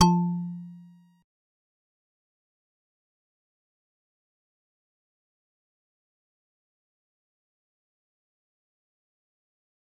G_Musicbox-F3-pp.wav